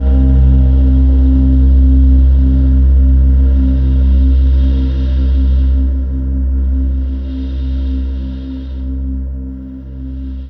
DM PAD1-19.wav